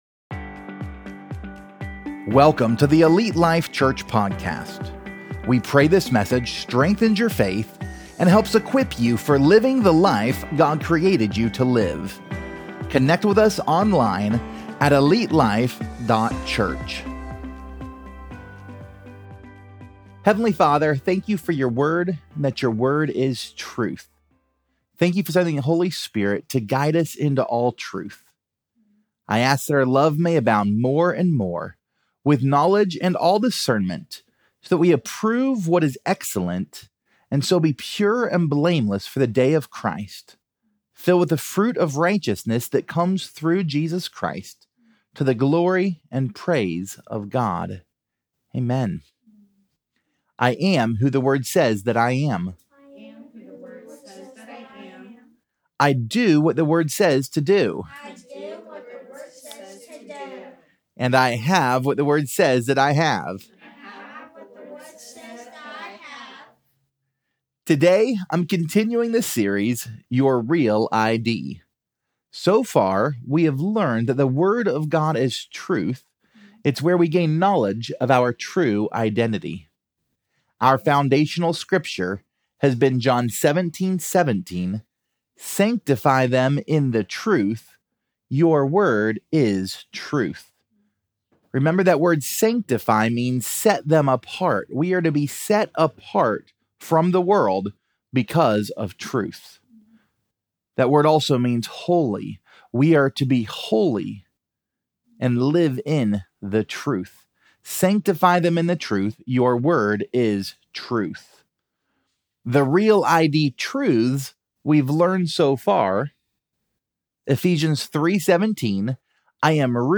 Pt 11: Home Sweet Home! | Your REAL ID Sermon Series